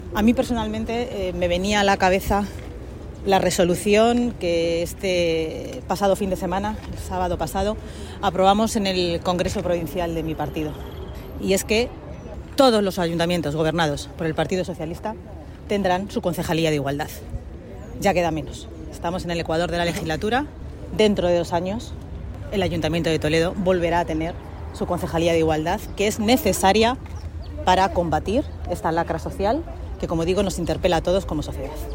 En declaraciones a los medios, Noelia de la Cruz ha avanzado que Toledo volverá a recuperar la Concejalía de Igualdad cuando gobierne el PSOE el Ayuntamiento al igual que en el resto de ayuntamientos que sean gobernados por el partido socialista.